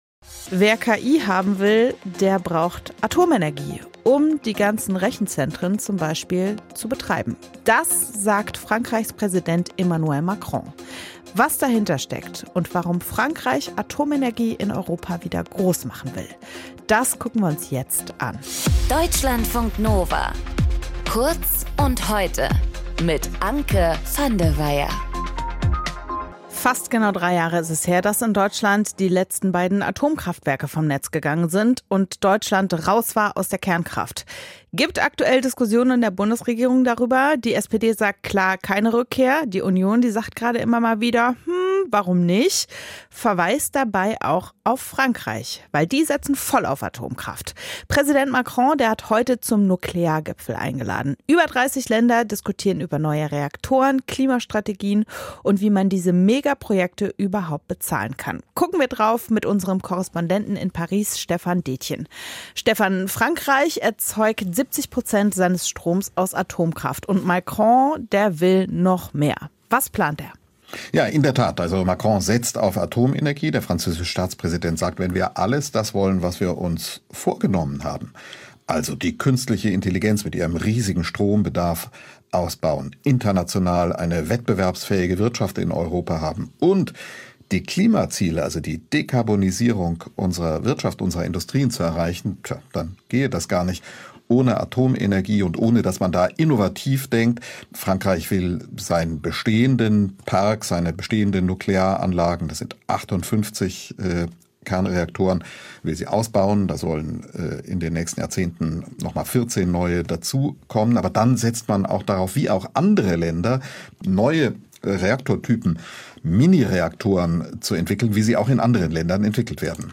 Moderatorin:
Gesprächspartner: